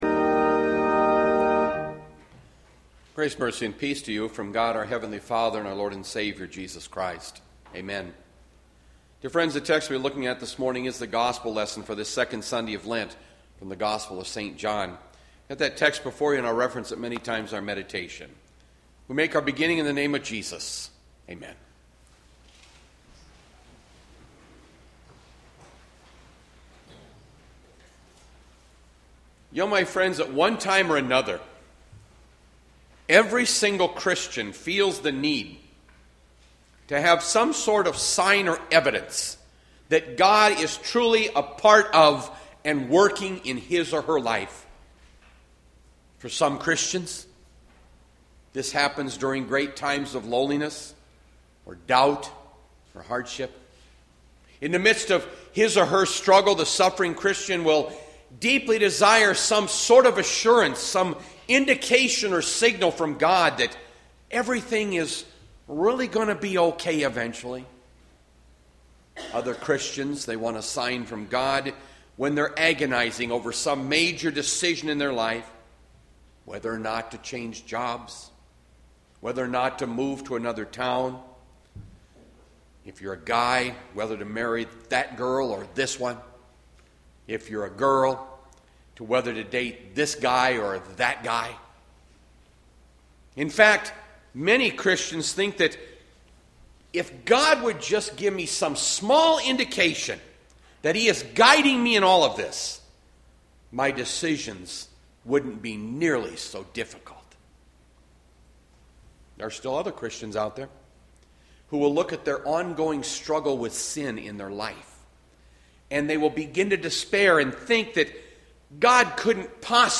Bethlehem Lutheran Church, Mason City, Iowa - Sermon Archive Mar 8, 2020